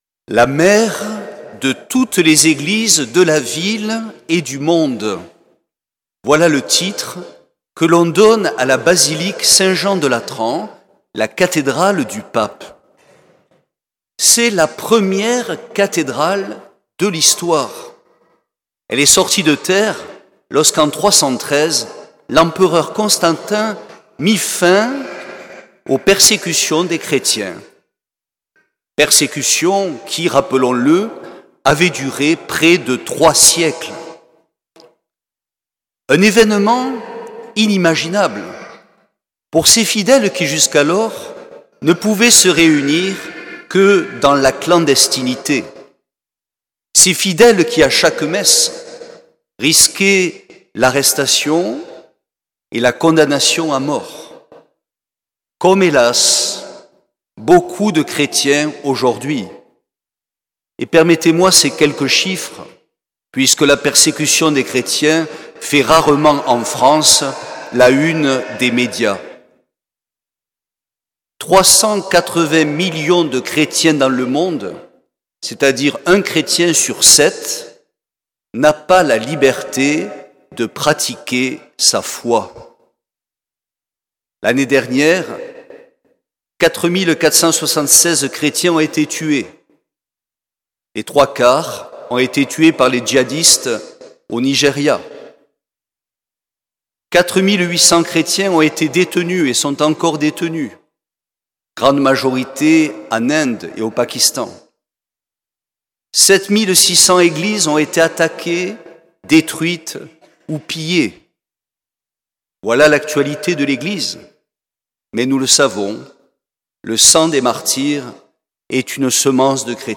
Homélie
solennité de la Dédicace de la basilique du Latran